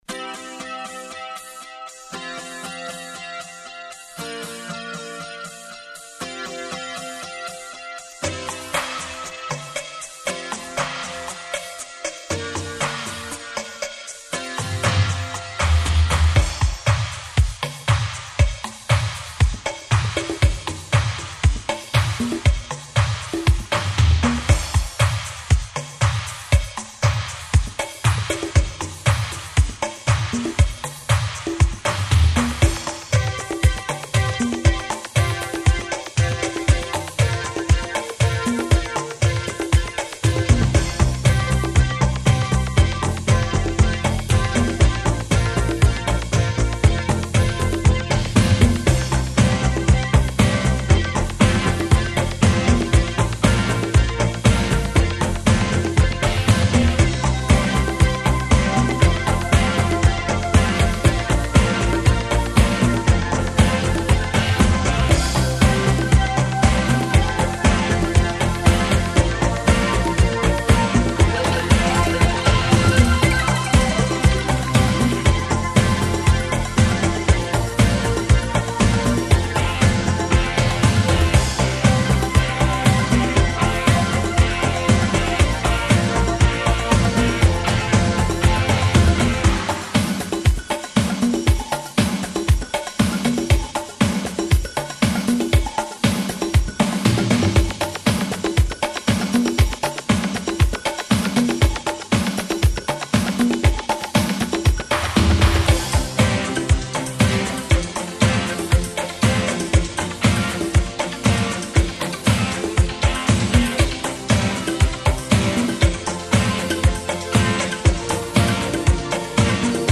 A “live” mix